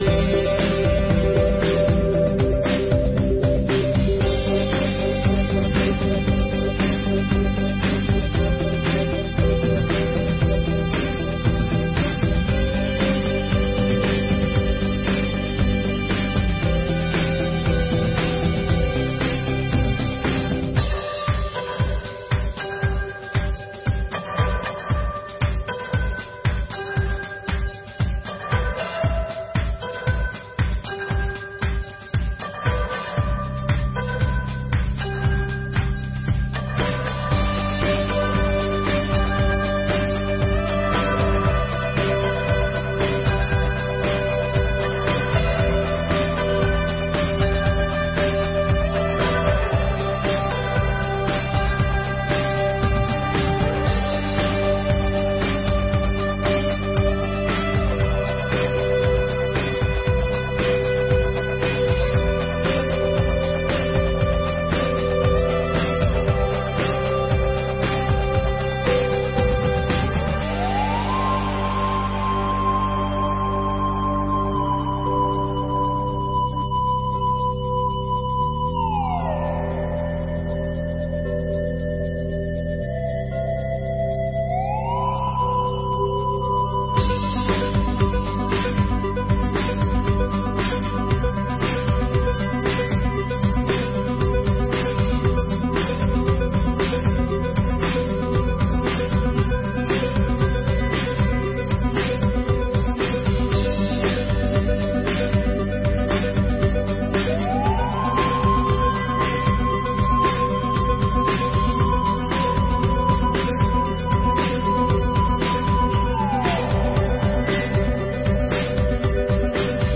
Sessões Solenes de 2023